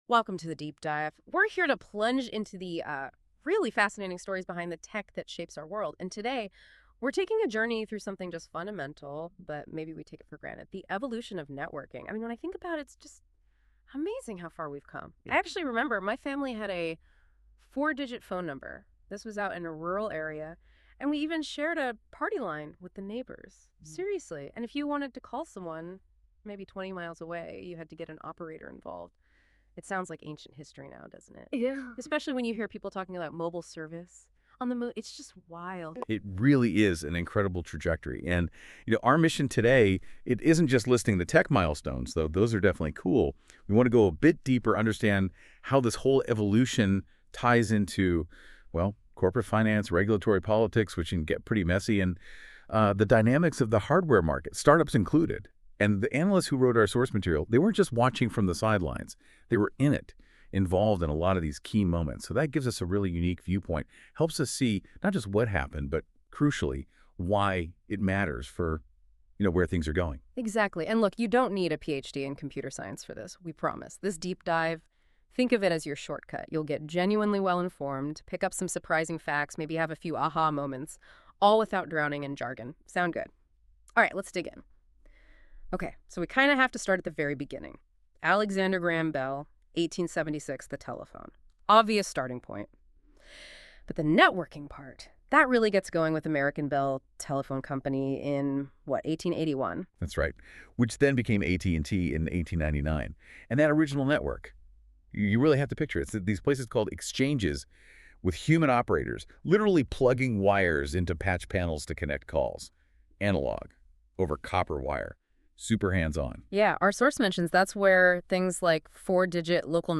An AI-Generated Podcast on Network Evolution